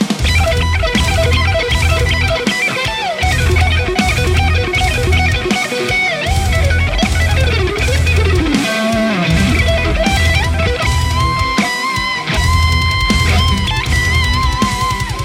The M1 Epona pack includes captures ranging from clean to full metal hi gain and everything in between plus my personal YouTube IR that I use in my demos are also included.
Lead Mix
RAW AUDIO CLIPS ONLY, NO POST-PROCESSING EFFECTS